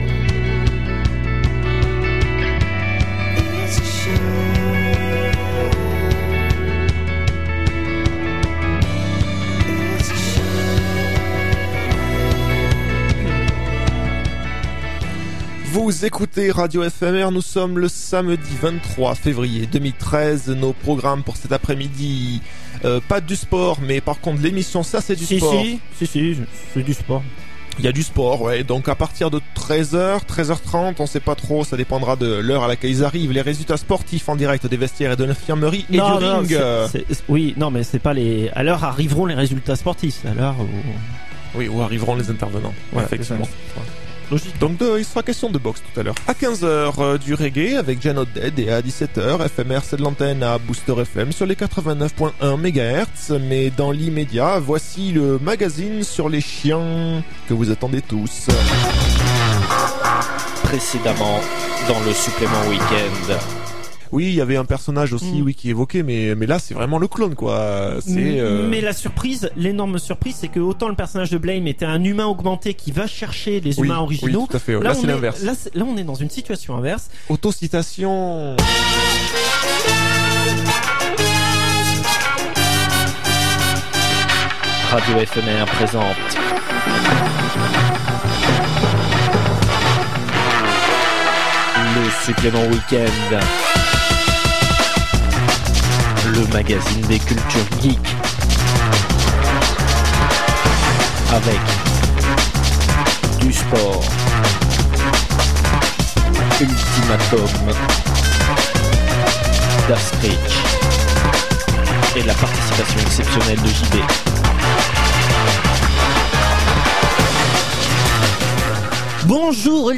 Enregistré à Angoulême en Janvier 2012.